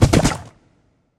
Minecraft Version Minecraft Version 1.21.5 Latest Release | Latest Snapshot 1.21.5 / assets / minecraft / sounds / mob / horse / skeleton / water / gallop2.ogg Compare With Compare With Latest Release | Latest Snapshot
gallop2.ogg